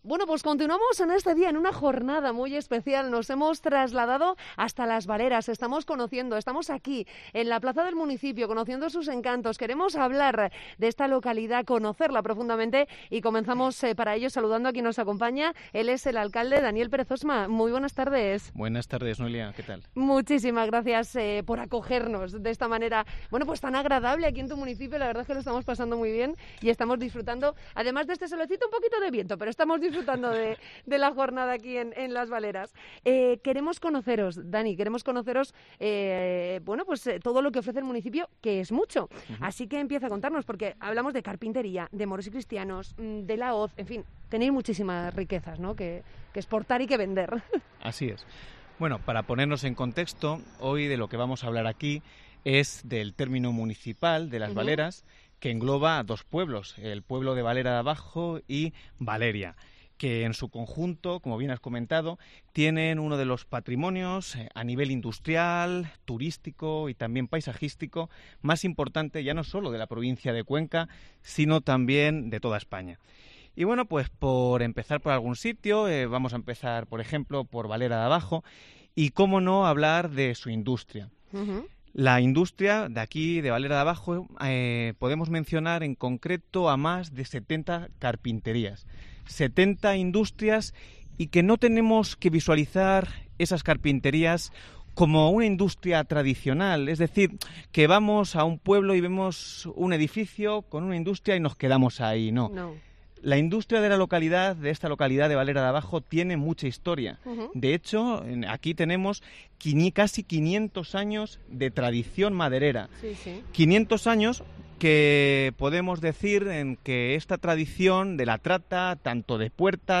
Entrevista con el alcalde de Las Valeras, Daniel Pérez Osma